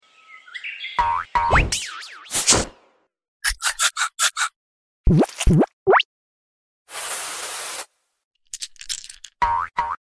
Jump Squirrel jump!🐿🫠🤣 Squishy ASMR